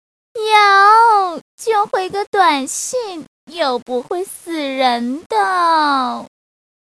分类: 短信铃声